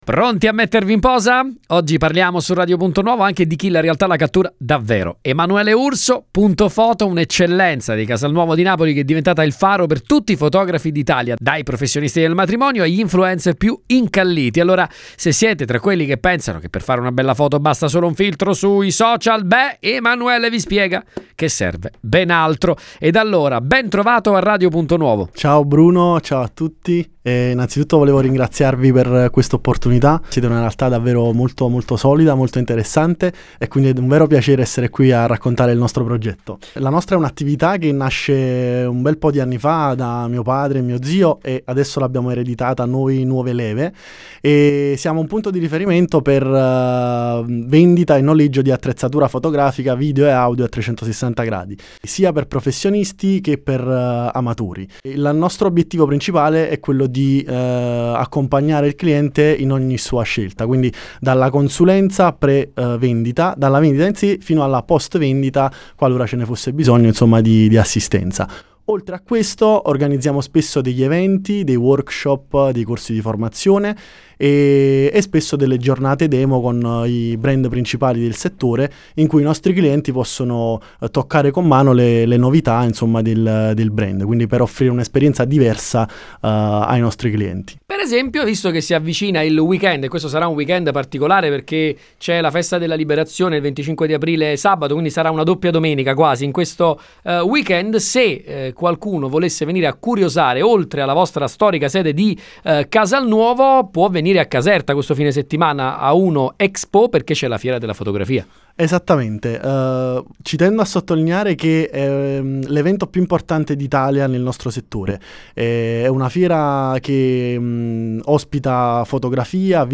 Intervista-Punto-Foto-04.mp3